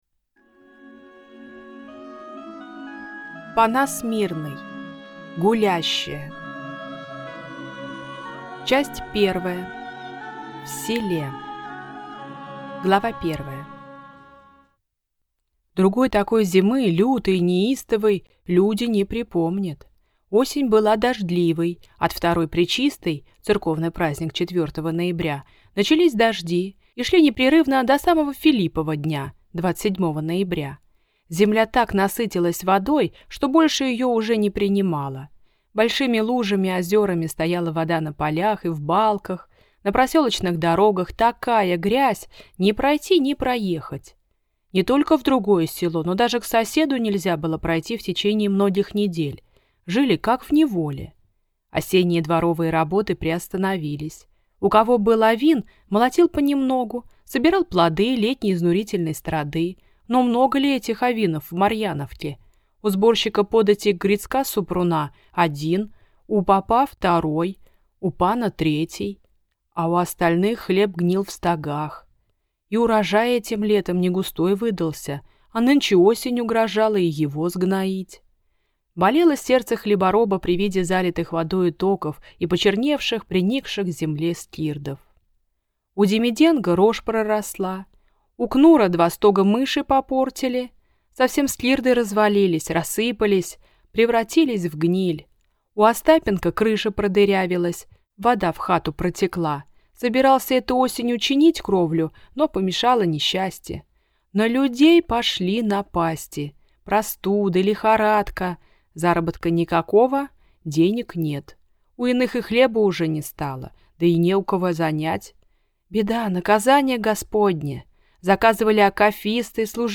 Аудиокнига Гулящая | Библиотека аудиокниг
Прослушать и бесплатно скачать фрагмент аудиокниги